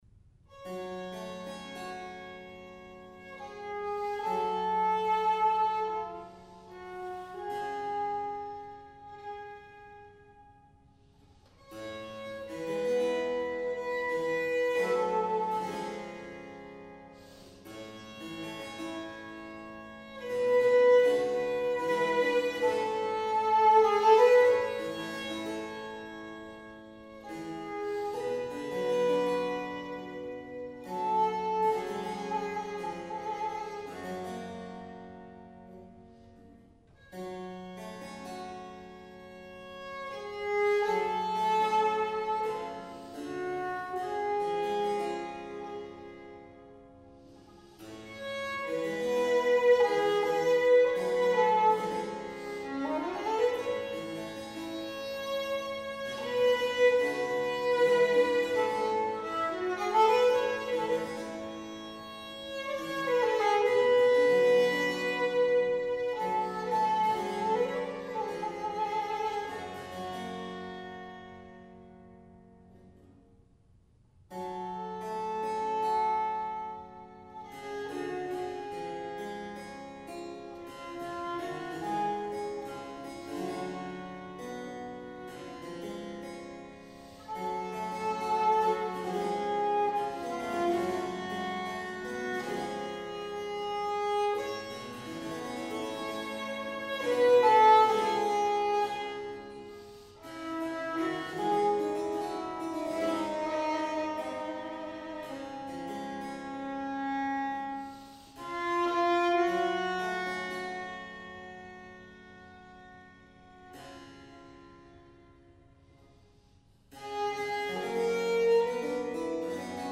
violin
harpsichord